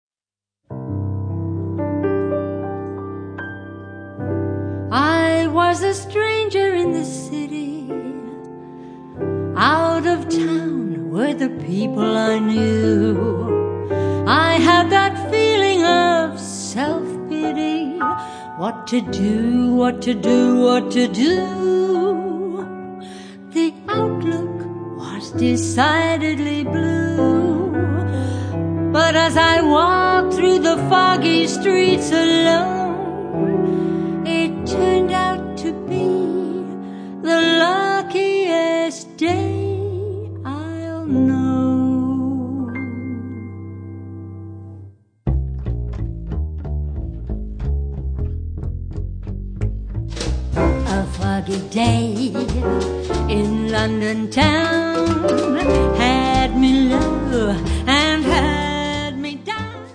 vibrafono
sassofono
pianoforte
contrabbasso
batteria